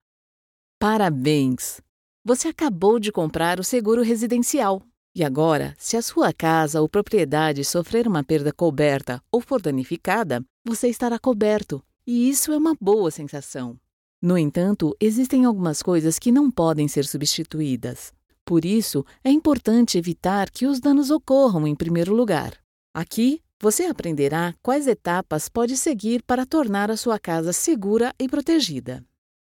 Amicale, Naturelle, Fiable, Polyvalente, Corporative
E-learning